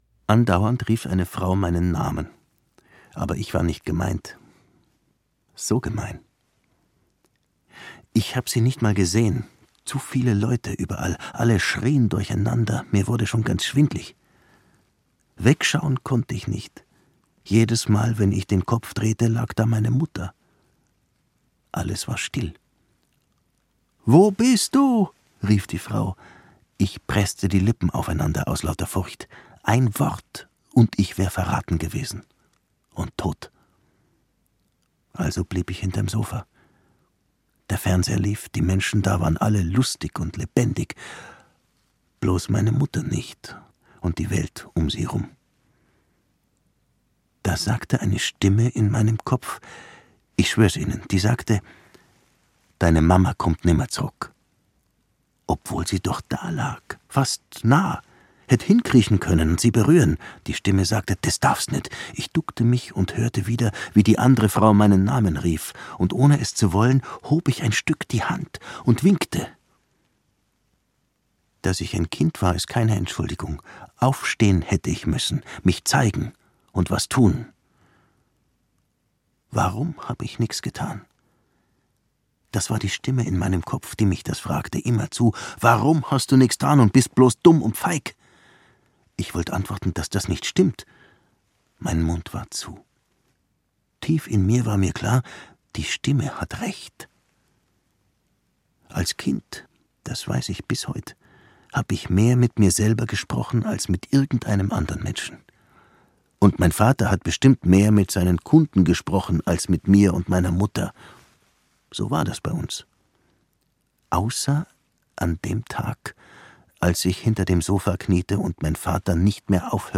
Der namenlose Tag - Friedrich Ani - E-Book + Hörbuch